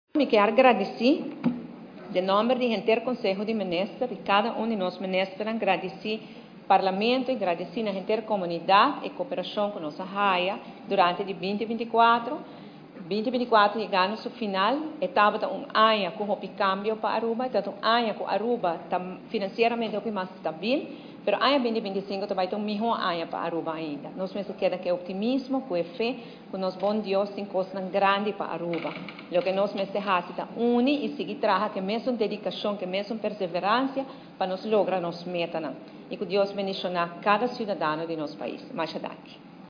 Gabinete Wever-Croes II tabata presente den sala di Parlamento pa trata e Presupuesto Supletorio
Prome-minister-Evelyn-Wever-Croes.mp3